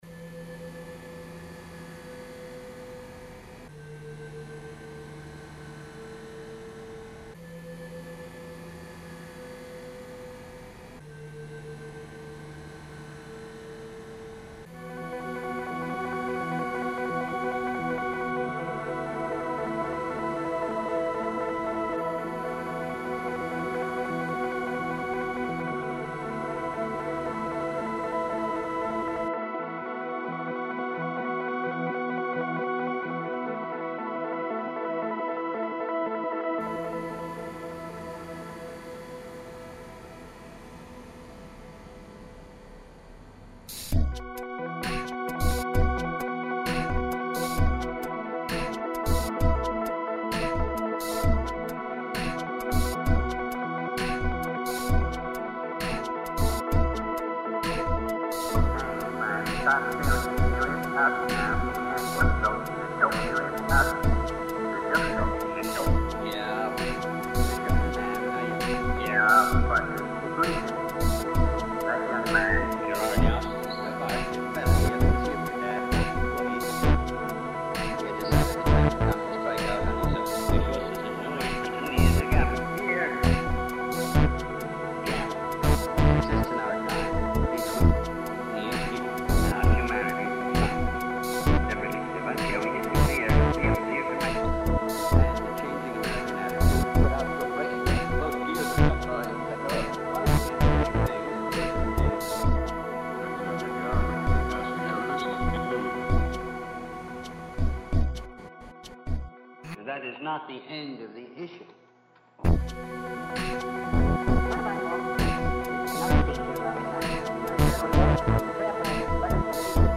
Rather than sing or play the guitar, I was just messing about and came up with some percussive sounds that I used to form the human beatbox in this new (very experimental) track. I’m not taking it any further than this because I enjoyed the discipline of making something in less than an hour; it’s fresh enough to be rough around the edges, but sounds good enough to leave it be!
The synth bass and ‘pads’ are done on the Moog synth and the other pad sound is a recording of ambient sounds in the London Underground that I made a few years ago.